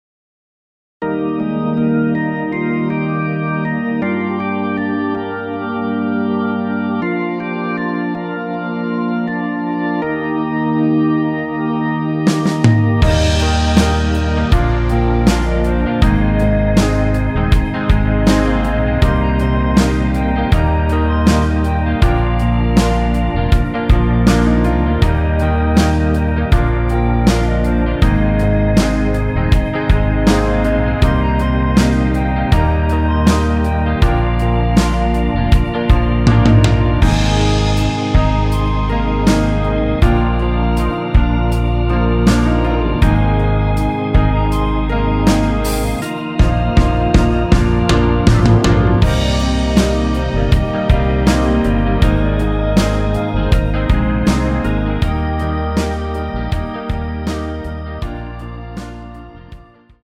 원키 멜로디 포함된 1절후 바로 후렴으로 진행 됩니다.(아래의 가사를 참조하세요)
Eb
멜로디 MR이라고 합니다.
앞부분30초, 뒷부분30초씩 편집해서 올려 드리고 있습니다.
중간에 음이 끈어지고 다시 나오는 이유는